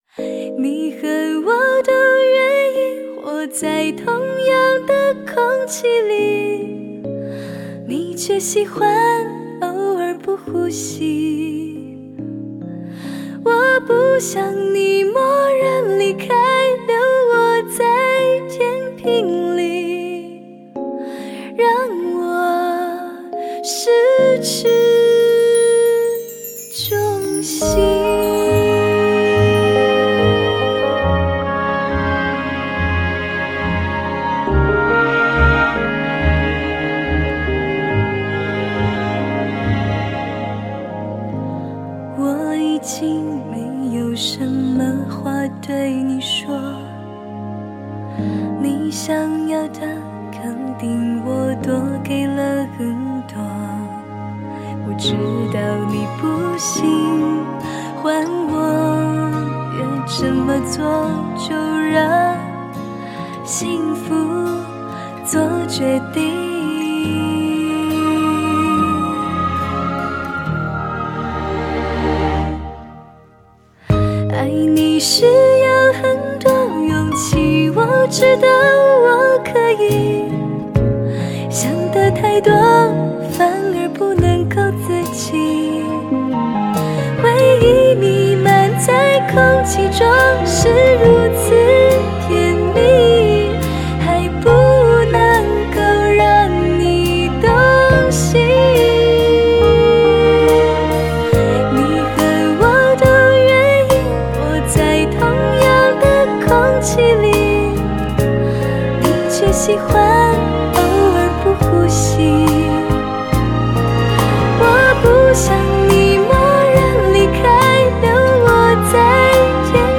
旋律好听 配以唯美的弦乐
曼妙的音符 清澈的音色 收放自如的演唱
夏日清新和爽朗的透明感和温暖度四处游走